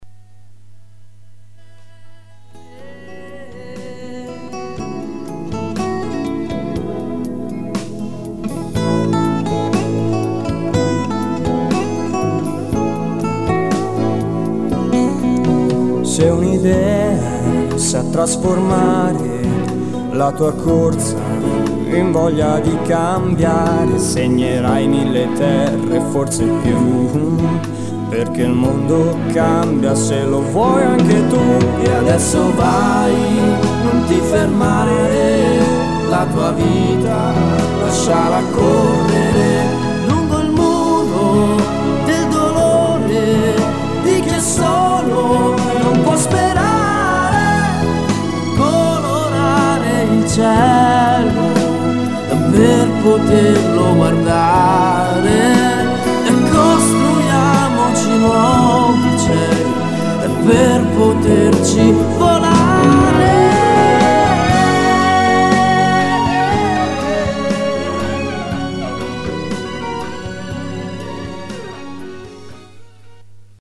inno